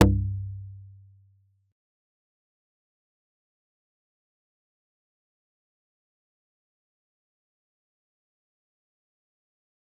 G_Kalimba-C2-pp.wav